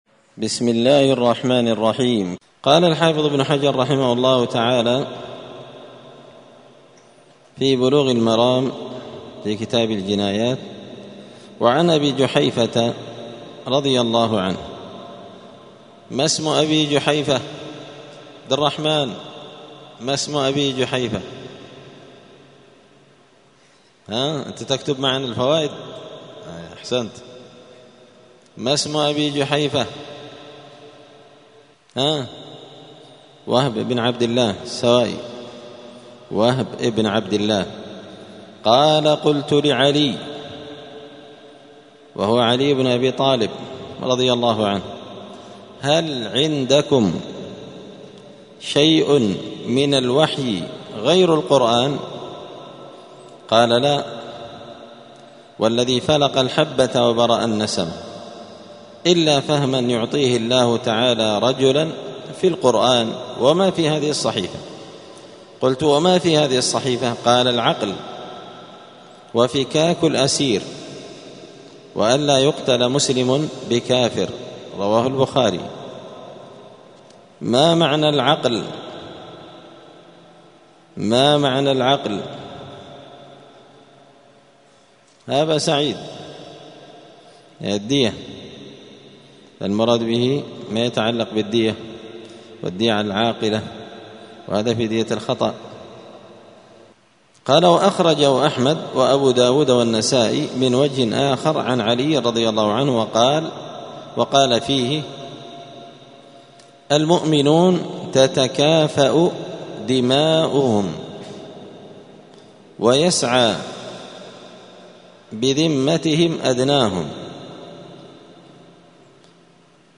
دار الحديث السلفية بمسجد الفرقان بقشن المهرة اليمن 📌الدروس اليومية